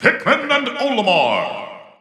The announcer saying Olimar's name in Japanese and Chinese releases of Super Smash Bros. 4 and Super Smash Bros. Ultimate.
Olimar_Japanese_Announcer_SSB4-SSBU.wav